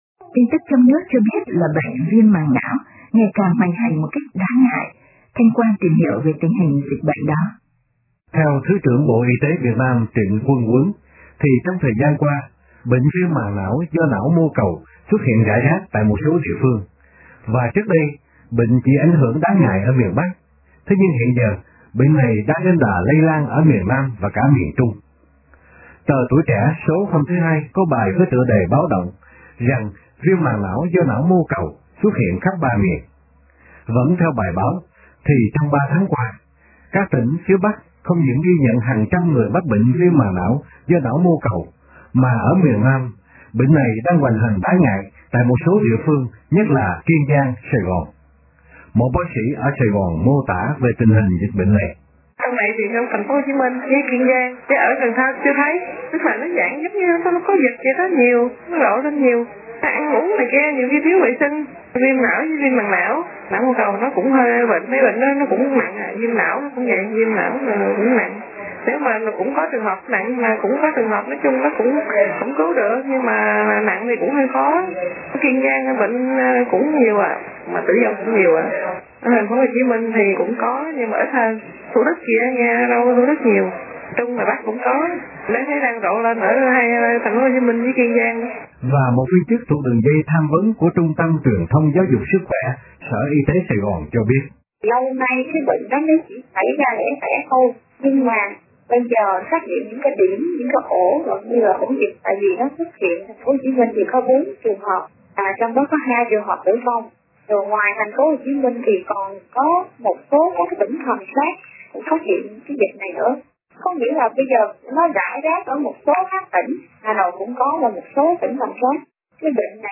Một bác sĩ ở Saigòn mô tả về tình hình dịch bệnh này.